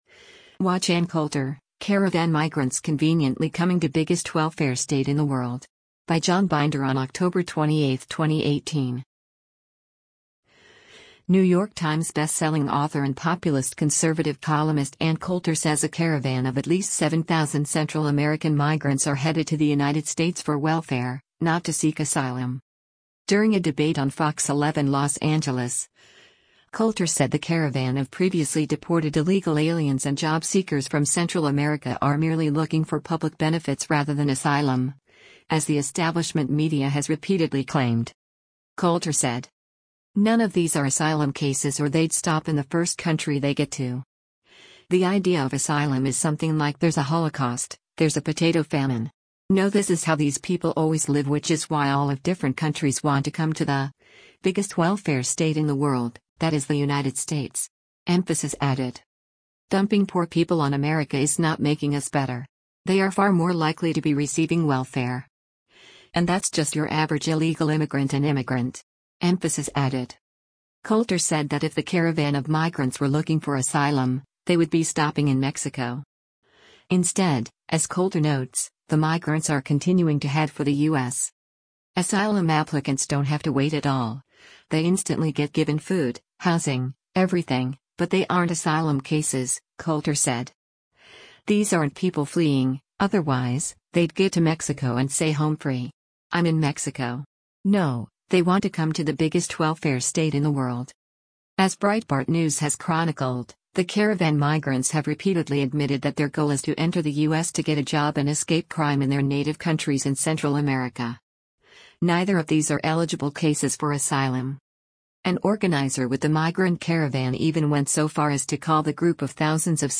During a debate on FOX11 Los Angeles, Coulter said the caravan of previously deported illegal aliens and job-seekers from Central America are merely looking for public benefits rather than asylum, as the establishment media has repeatedly claimed.